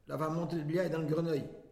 Localisation Saint-Christophe-du-Ligneron
Catégorie Locution